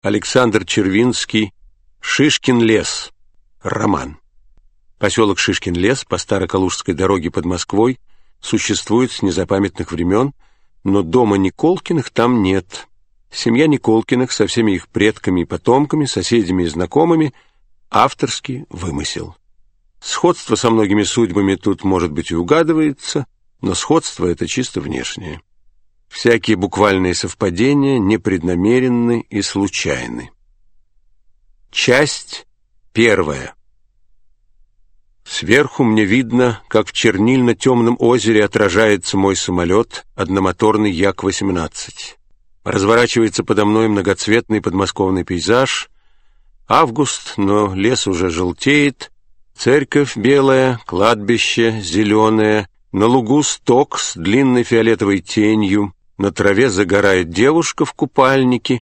Аудиокнига Шишкин лес. Читает Вениамин Смехов | Библиотека аудиокниг